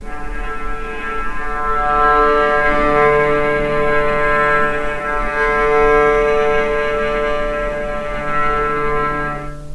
Strings / cello / sul-ponticello
vc_sp-D3-pp.AIF